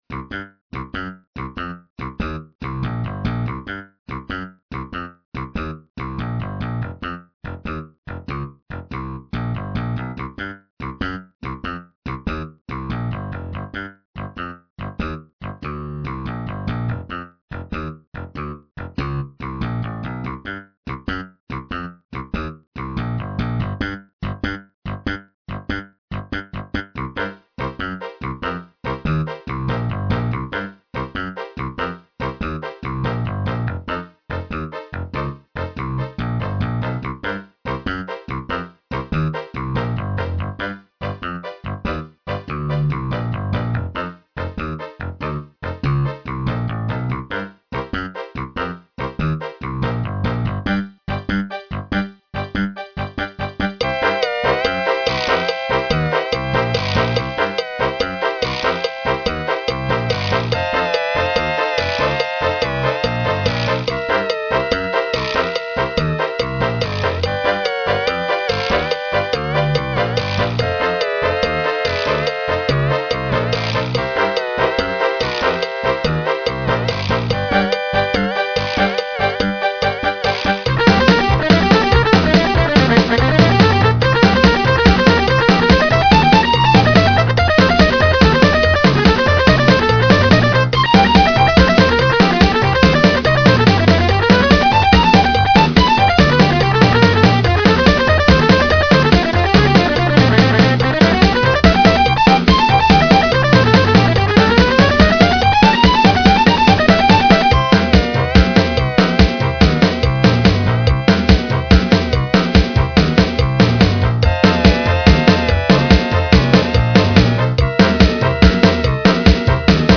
Here is a patriotic song for you. This is a redo with stereo effects. Listen for the various service songs.